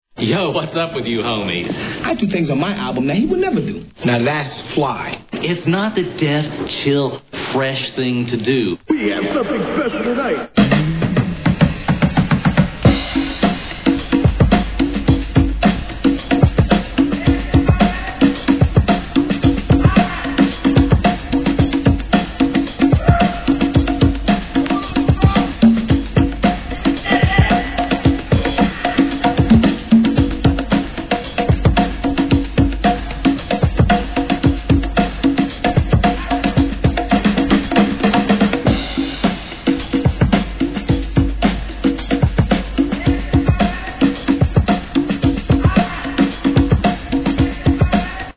HIP HOP/R&B
ブレイクビーツ、コスリネタ集!!!